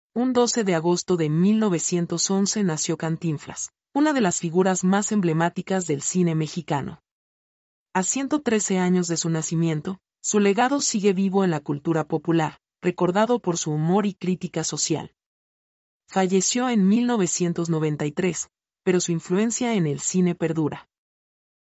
mp3-output-ttsfreedotcom-56-1-1.mp3